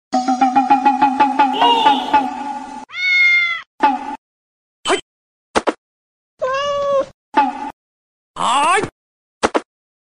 SFX轻松的搞笑氛围音音效下载
SFX音效